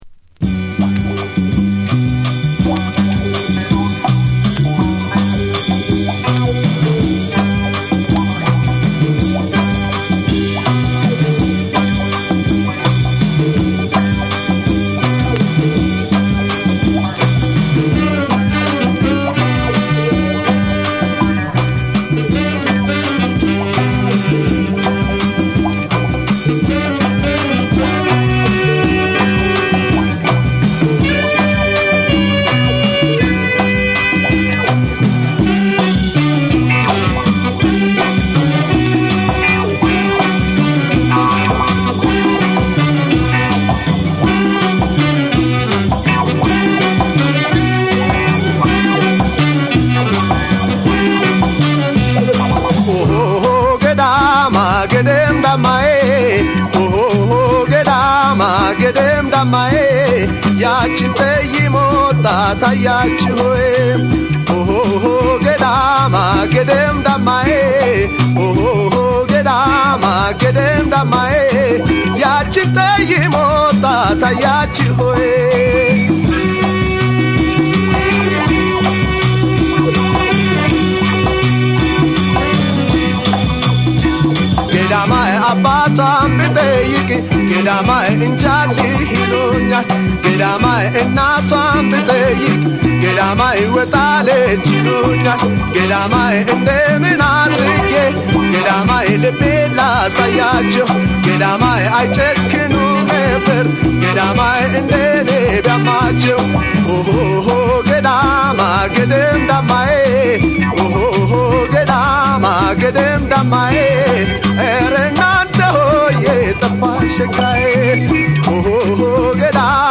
ジャズ、ファンク、ロック、民謡が独自に融合した“エチオ・グルーヴ”の真髄を、名曲・珍曲・発掘音源で網羅！
WORLD